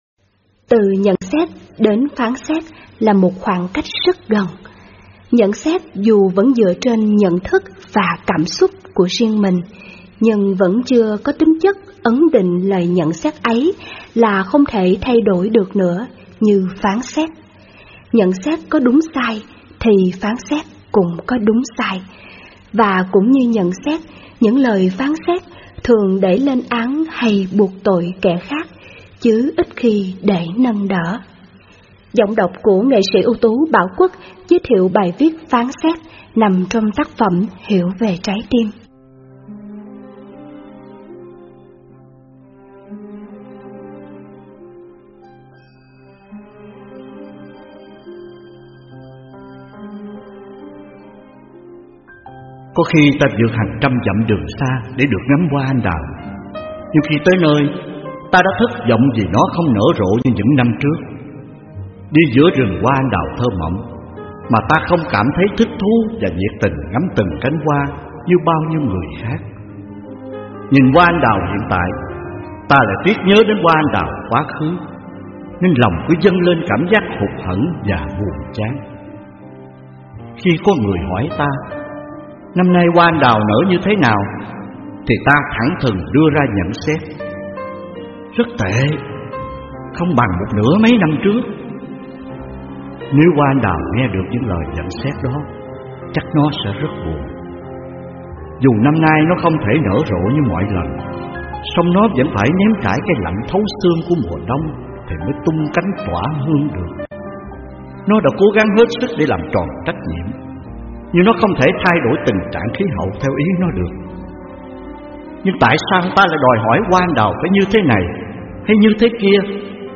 Sách nói mp3